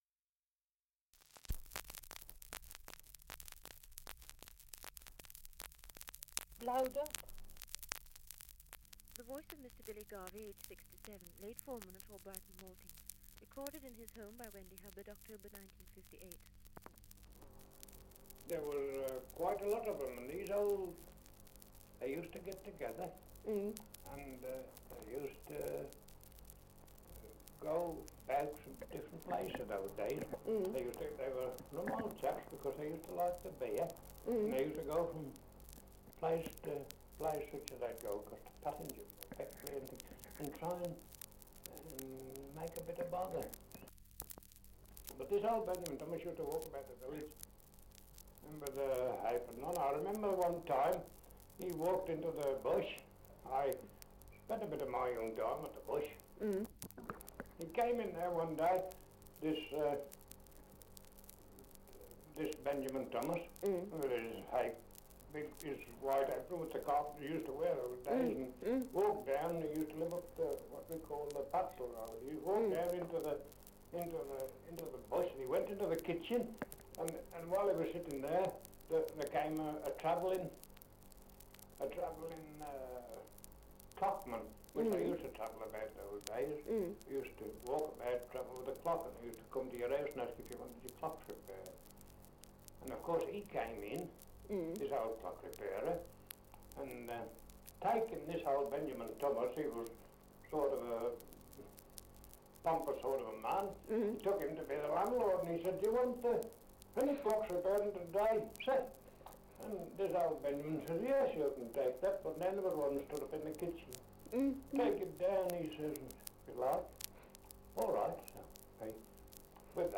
Dialect recording in Albrighton, Shropshire
78 r.p.m., cellulose nitrate on aluminium